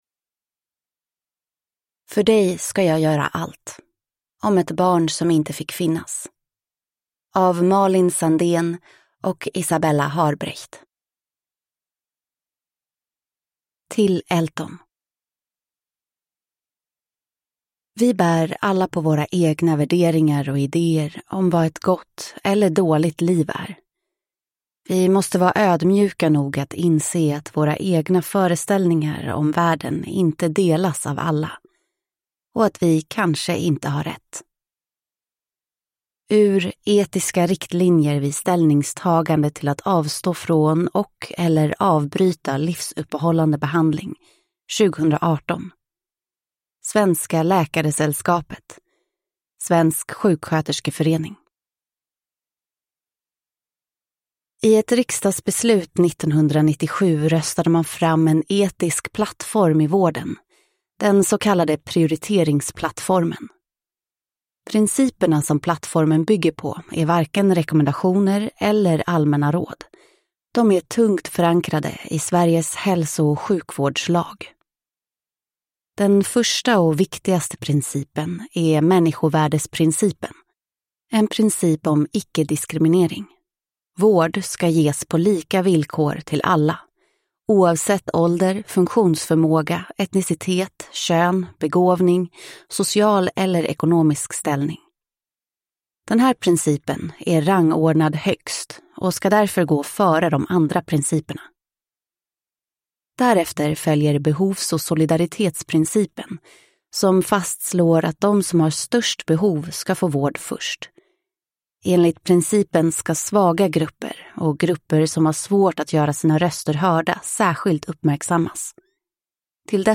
För dig ska jag göra allt : om ett barn som inte fick finnas – Ljudbok